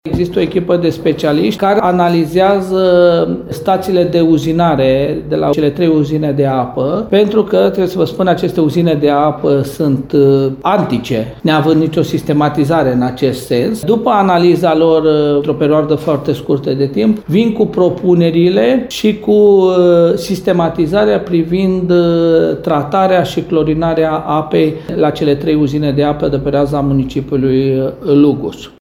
Pentru ca locuitorii orașului să beneficieze de apă curată, Aquatim va intensifica forajele și extinderea rețelei, spune primarul Călin Dobra.